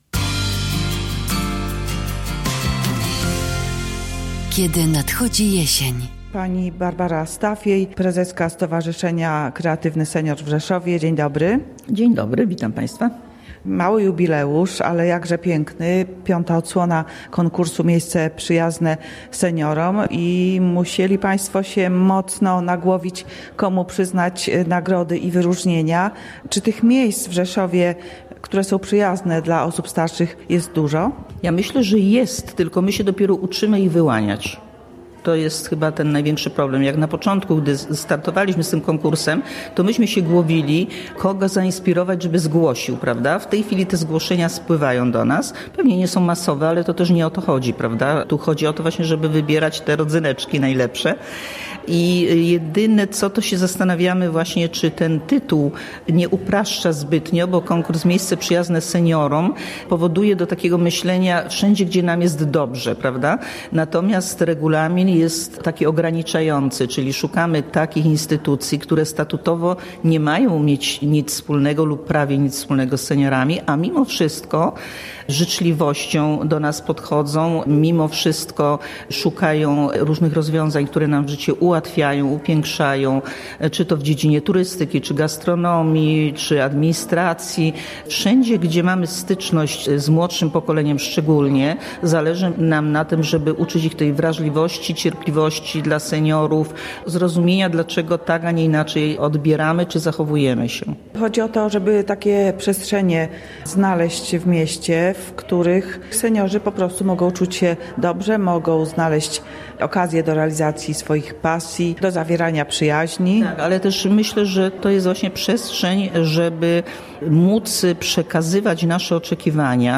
W audycji Kiedy nadchodzi jesień z przedstawicielami nagrodzonych instytucji i wojewodą Teresą Kubas-Hul rozmawiała